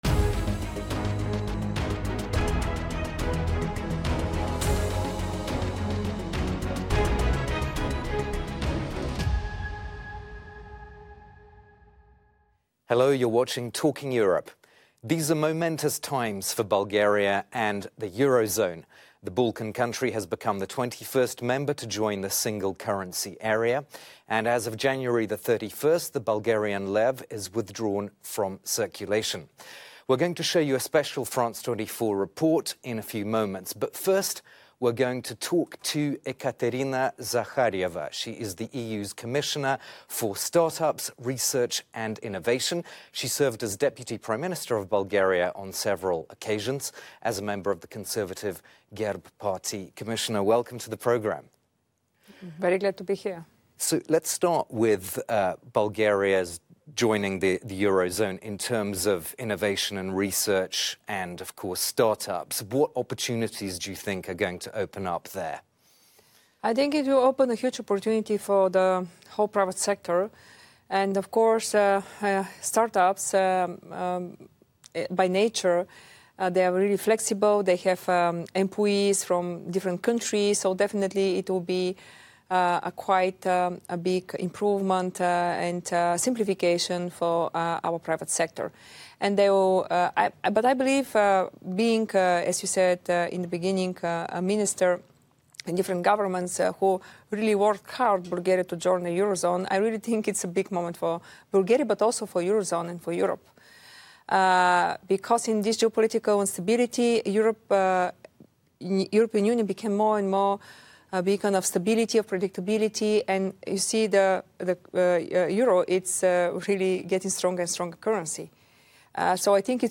In this programme, we also speak to Ekaterina Zaharieva, the EU Commissioner for Startups, Research and Innovation, and a former deputy prime minister of Bulgaria .